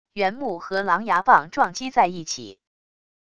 圆木和狼牙棒撞击在一起wav音频